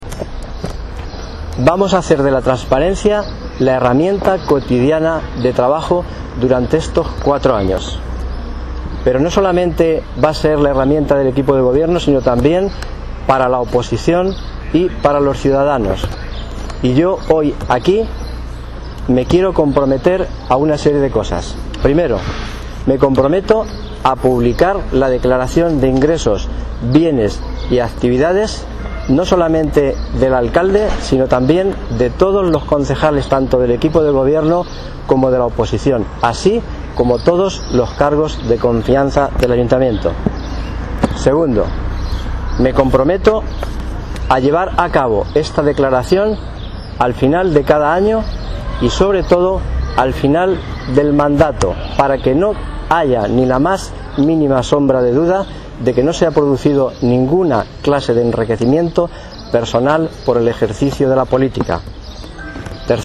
en una comparecencia a las puertas del Ayuntamiento